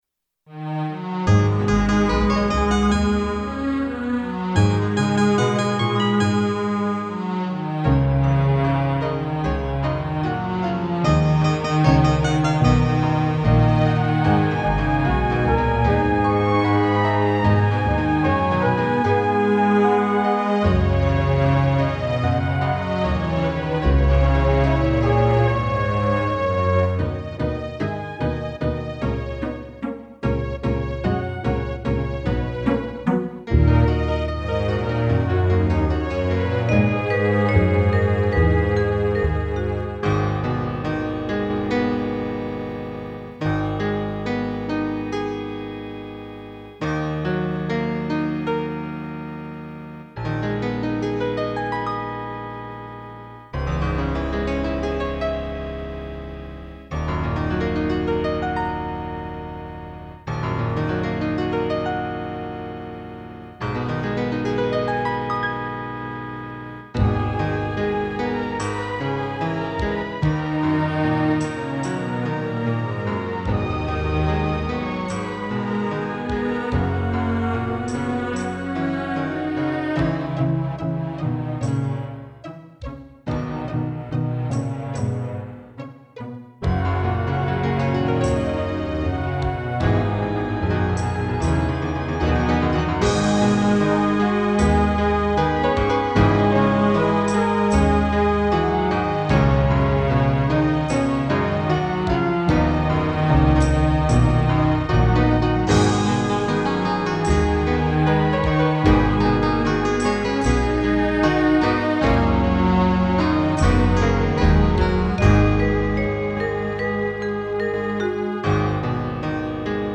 (a) 伴奏